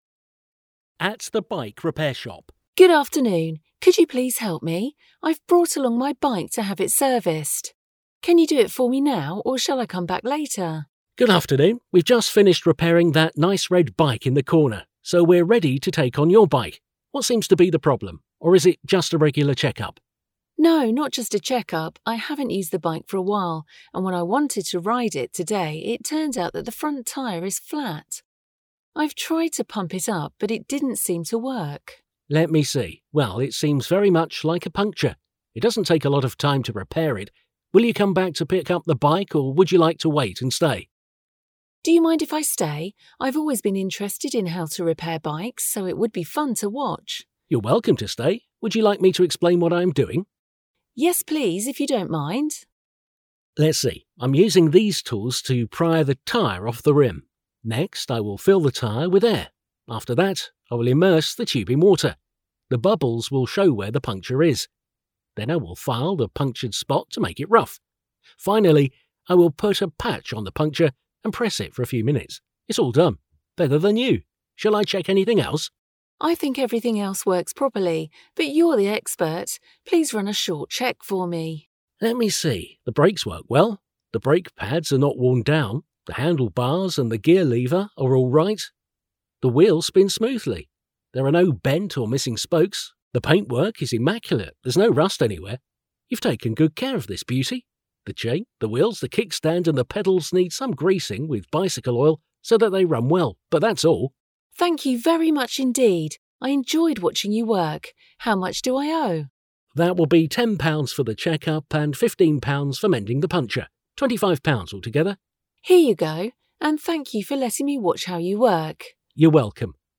A magazin 80. oldalán található párbeszédet hallgathatod meg itt.